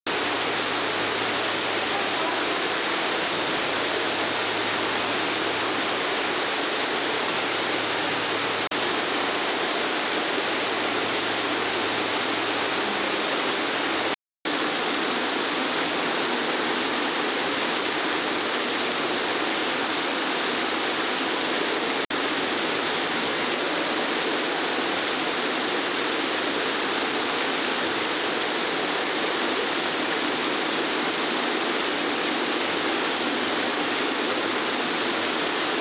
湧水トンネル１
yusui-tunnel1.wav